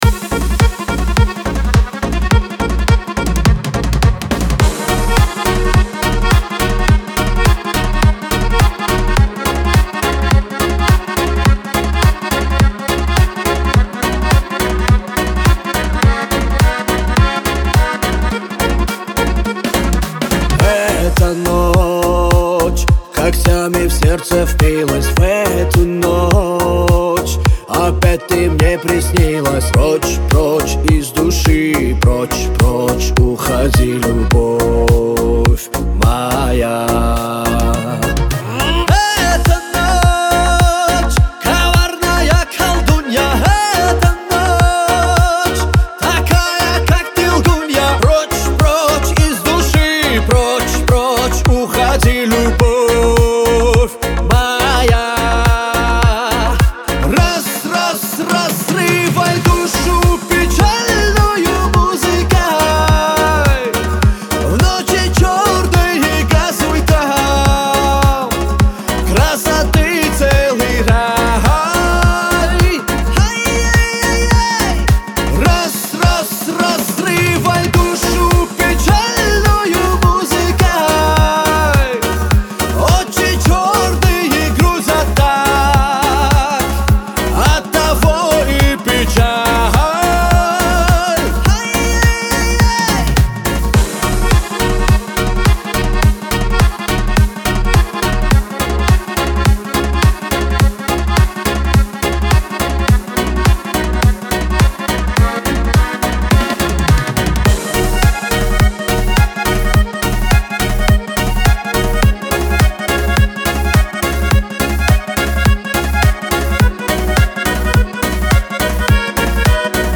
Лирика , Кавказ – поп